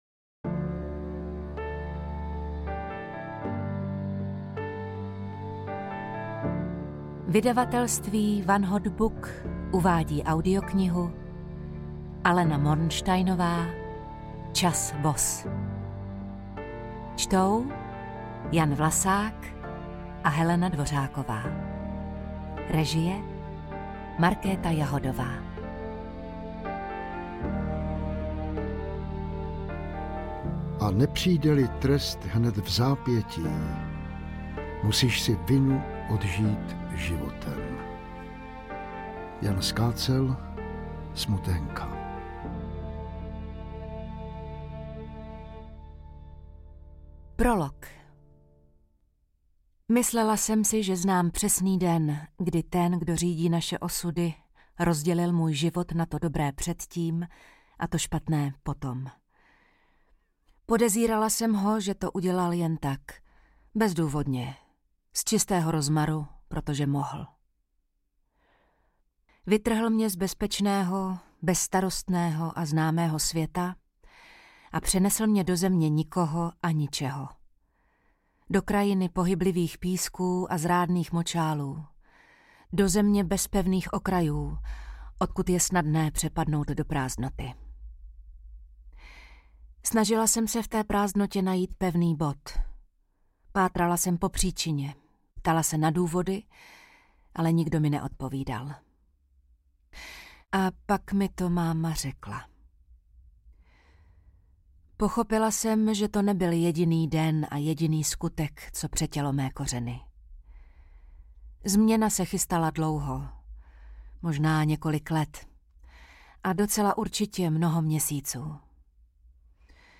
Interpreti:  Helena Dvořáková, Jan Vlasák